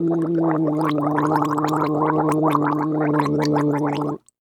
human
Gargle Water Male